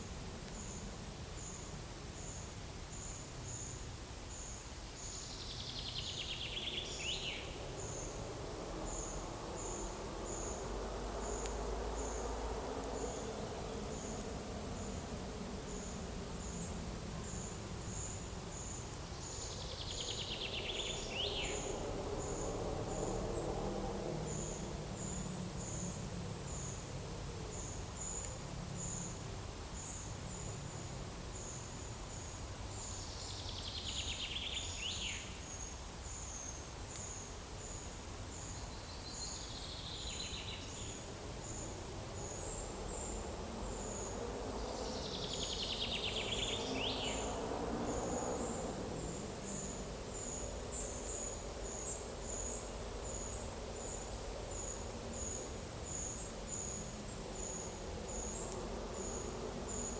Monitor PAM
Certhia familiaris
Certhia brachydactyla
Fringilla coelebs
Turdus iliacus